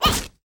Sfx Player Kick Sound Effect
sfx-player-kick-1.mp3